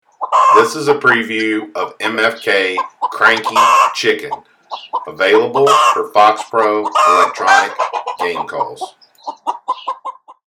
MFK Cranky Chicken – 16 bit
Recorded with the best professional grade audio equipment MFK strives to produce the highest